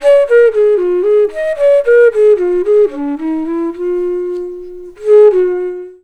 FLUTE-B10 -L.wav